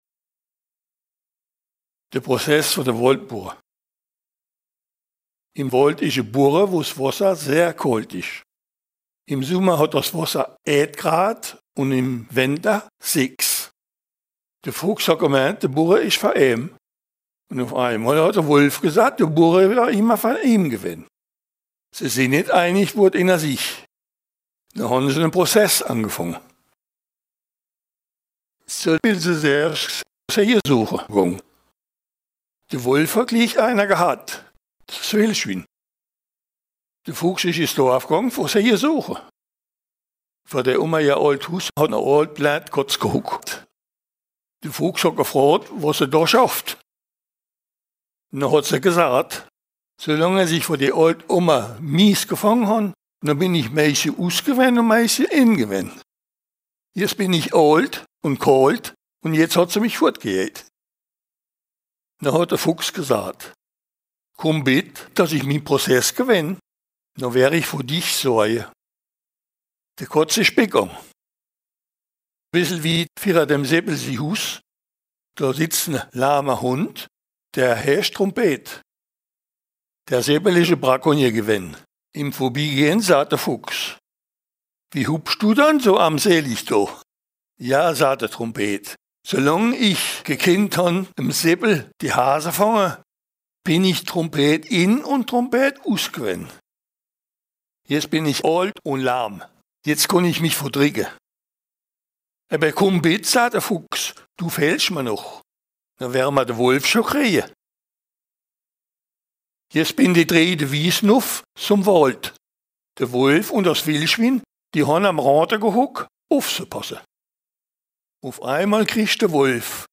Contes et récits en ditsch enregistrés dans les communes de Racrange, Vallerange, Bérig-Vintrange, Harprich, Eincheville, Viller-Béning, Viller, Viller-Boustroff et de Boustroff.